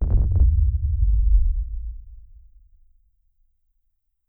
BassRumble12.wav